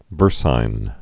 (vûrsīn)